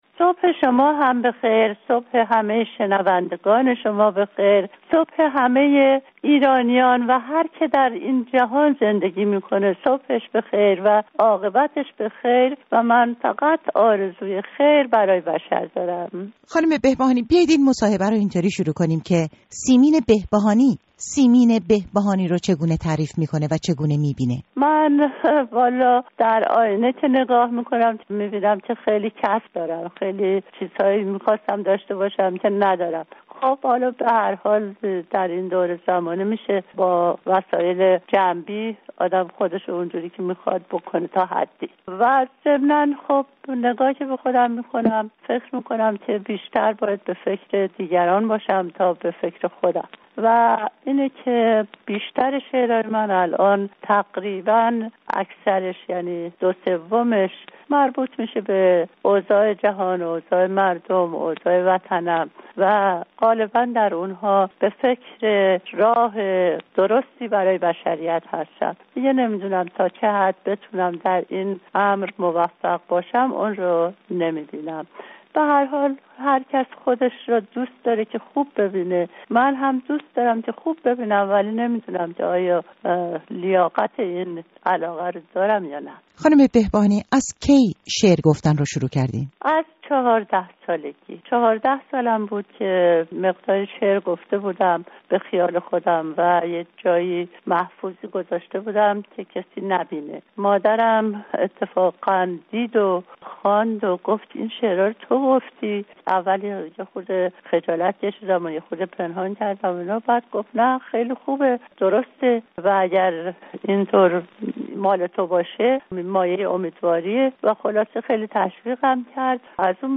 اين گفتگوی نسبتا مفصل را بشنويد:
مصاحبه